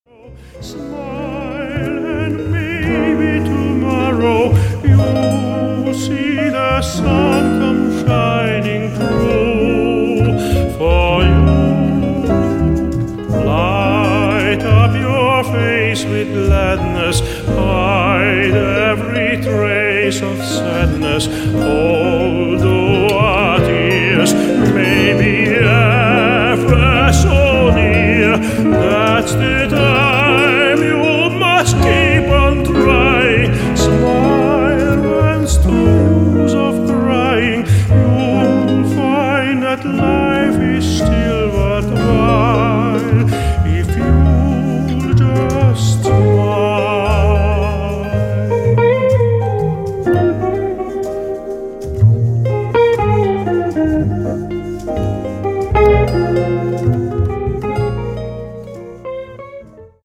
Grandioser Gesang